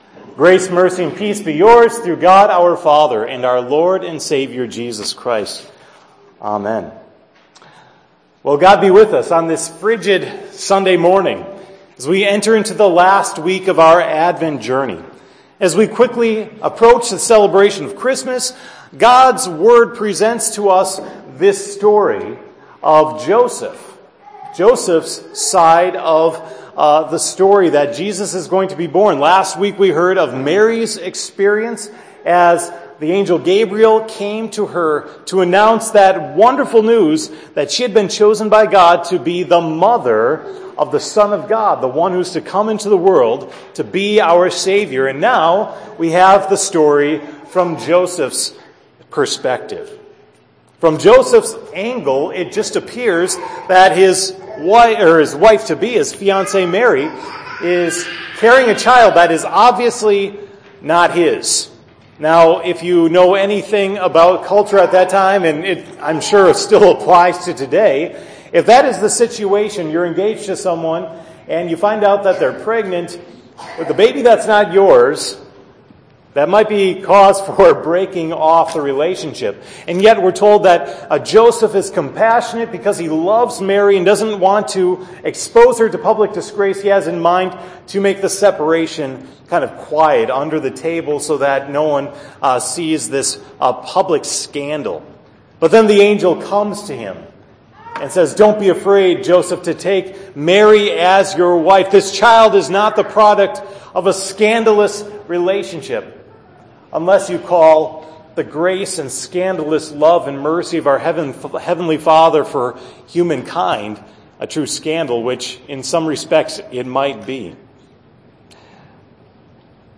“Love Is Here!” – Sermon for 12/18